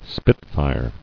[spit·fire]